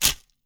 Close Combat Break Bone 2.wav